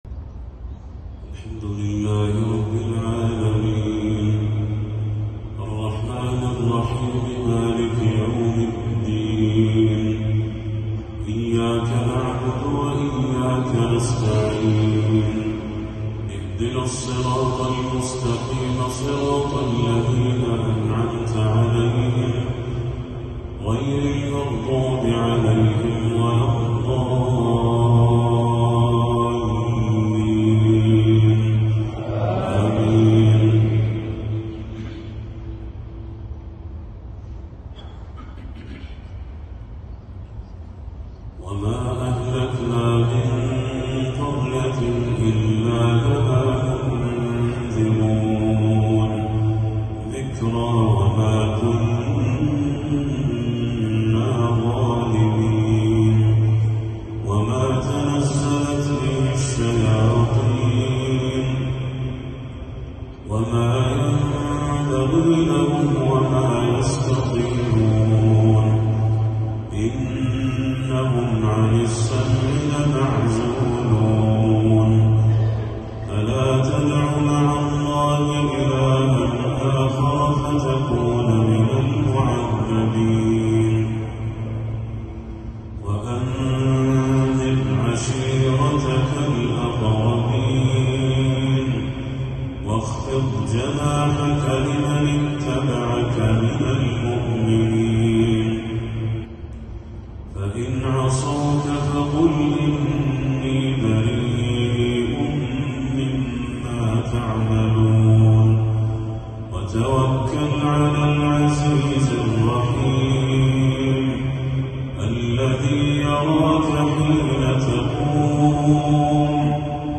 تلاوة تُسكن القلب لخواتيم سورتي الشعراء والعنكبوت للشيخ بدر التركي | عشاء 4 ربيع الأول 1446هـ > 1446هـ > تلاوات الشيخ بدر التركي > المزيد - تلاوات الحرمين